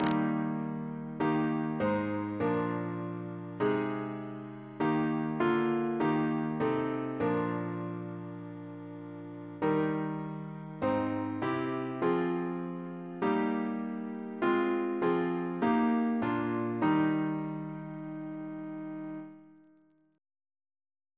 Double chant in E♭ Composer: Highmore Skeats, Jr. (1757-1831) Reference psalters: ACP: 43; PP/SNCB: 26; RSCM: 118